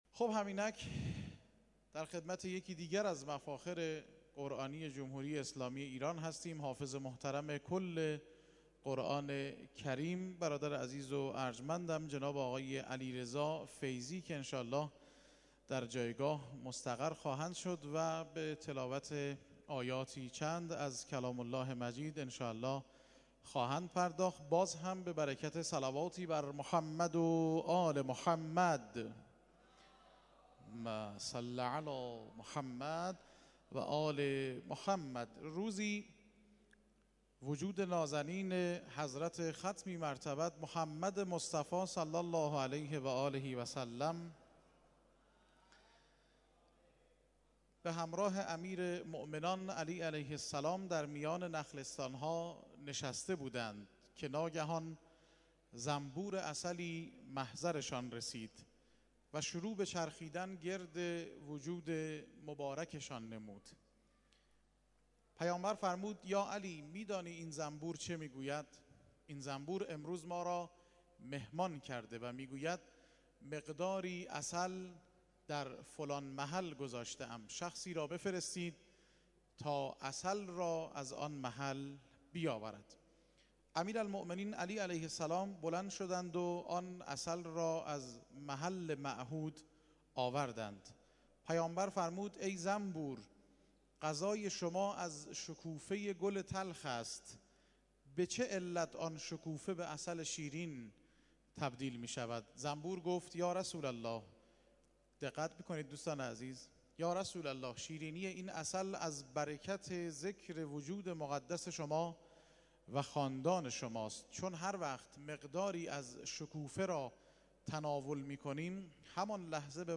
تلاوت‌های محفل قرآنی آستان عبدالعظیم(ع)+ صوت
گروه جلسات و محافل: محفل انس با قرآن کریم این هفته آستان عبدالعظیم الحسنی(ع) با تلاوت قاریان ممتاز و بین‌المللی کشورمان برگزار شد.